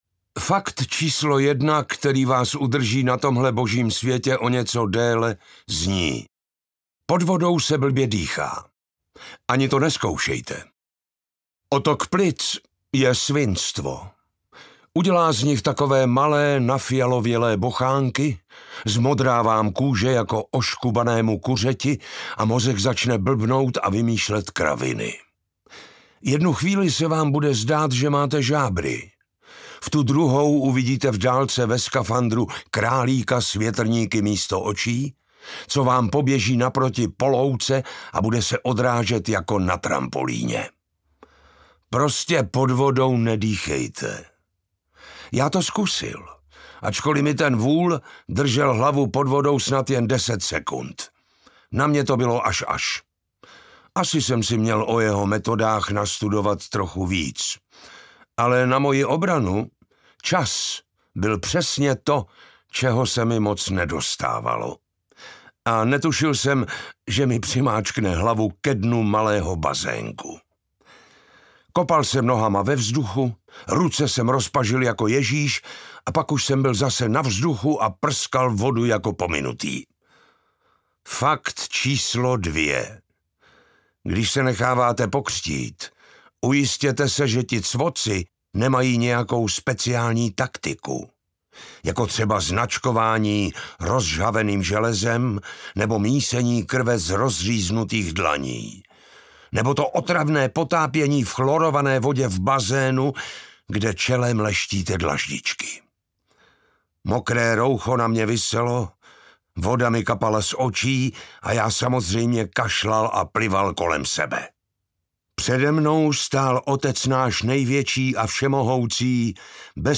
Kapka hněvu audiokniha
Ukázka z knihy
kapka-hnevu-audiokniha